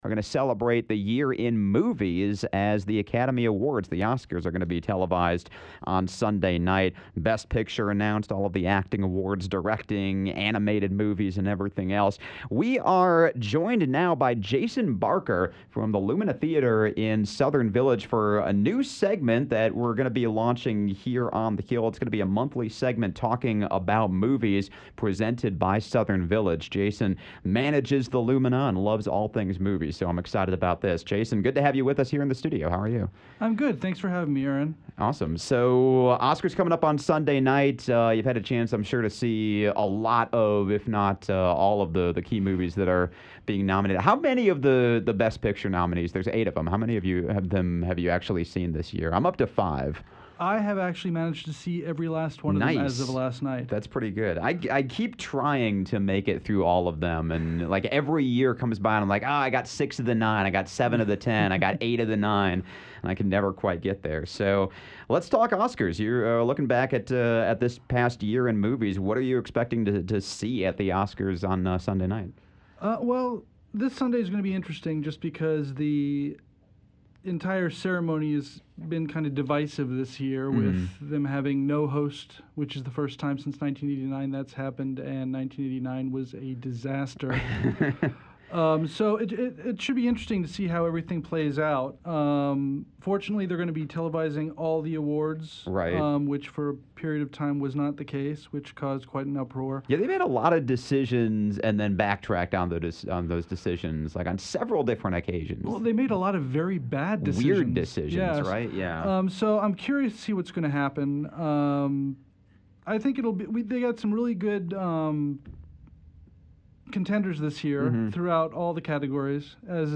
(Conversation sponsored by Southern Village.)